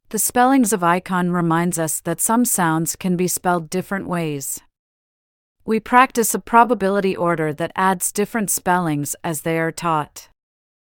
Spellings-Of-icon-lesson-AI.mp3